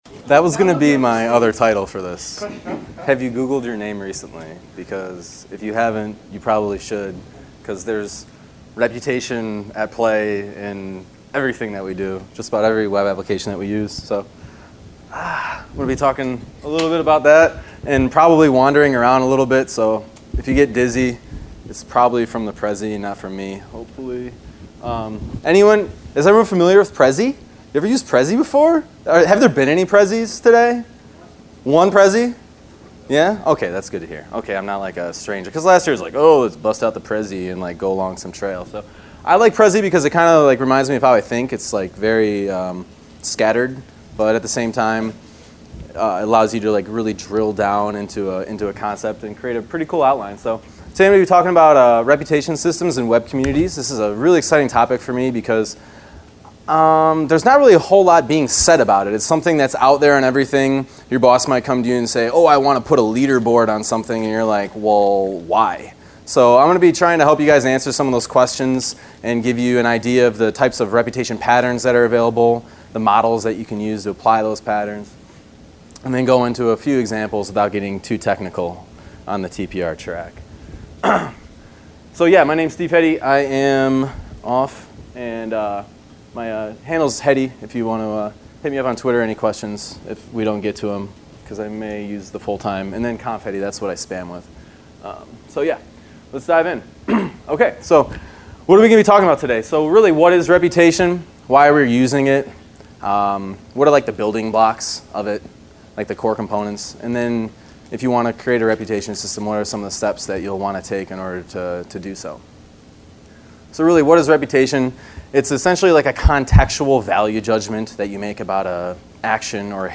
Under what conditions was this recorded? Session Details - HighEdWeb 2010: The National Conference for Higher Education Web Professionals